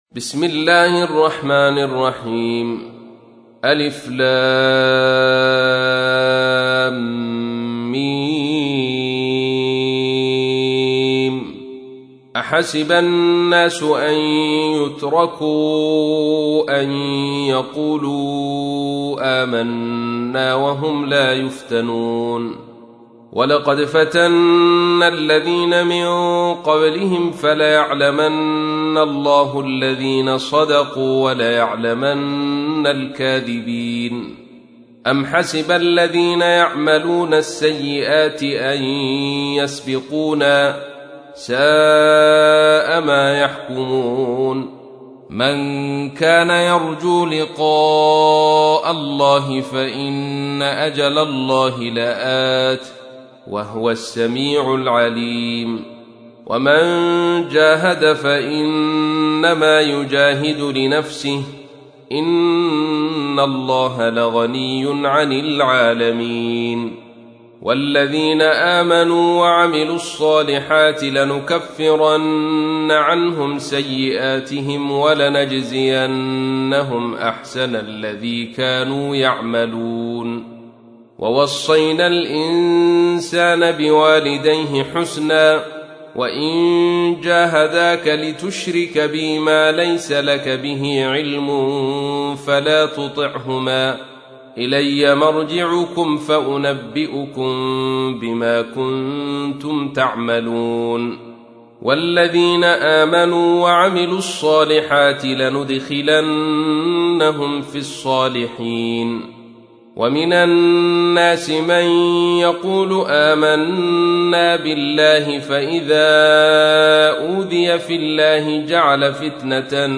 تحميل : 29. سورة العنكبوت / القارئ عبد الرشيد صوفي / القرآن الكريم / موقع يا حسين